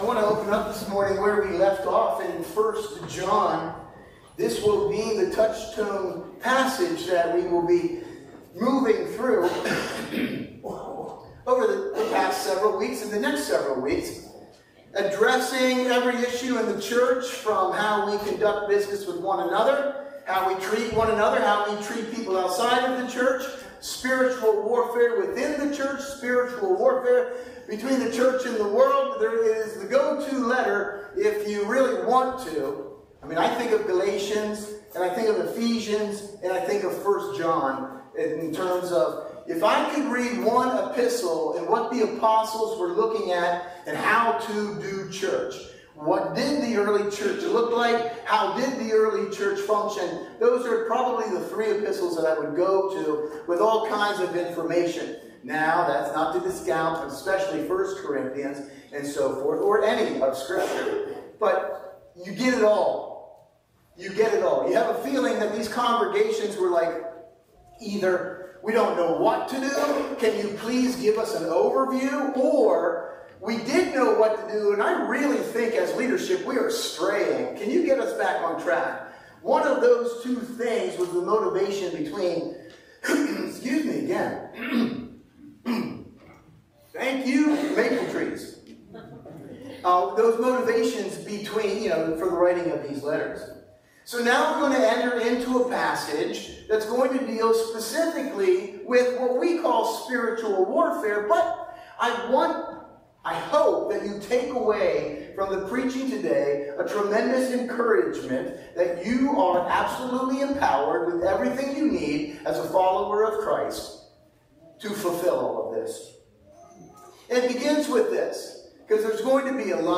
Sunday Morning Service – May 5, 2024
Today was a special day of music, prayer, and time in the word. We open 1 John 2 and discover all we need to walk victoriously in this wicked world.